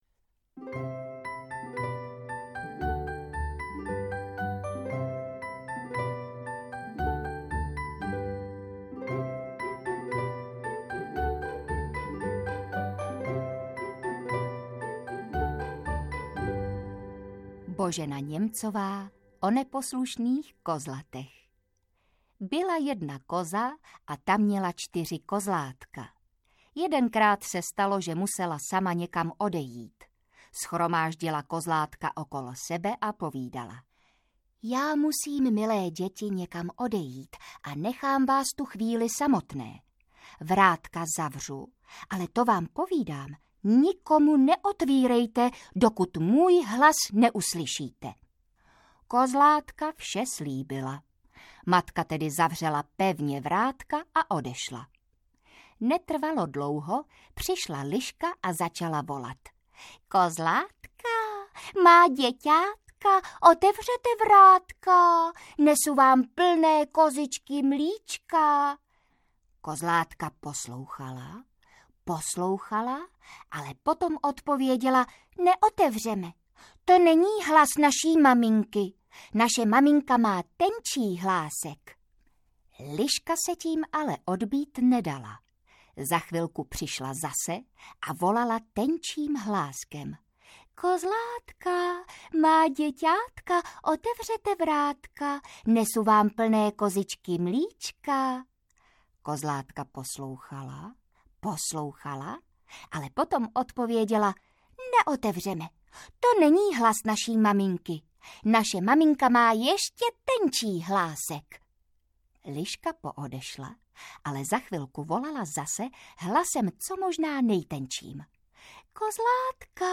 Pohádkové poslouchánky audiokniha
Ukázka z knihy